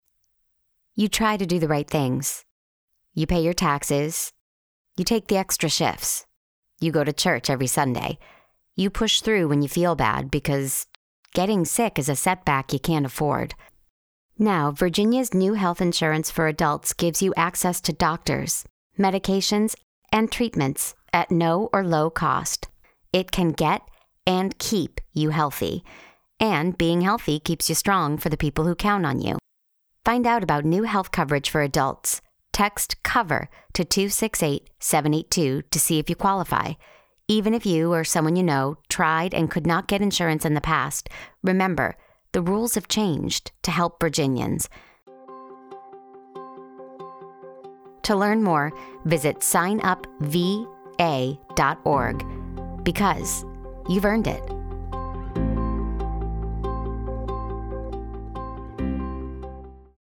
It also includes several radio ads (listen
VHCF-Radio-Spot-Southwest.mp3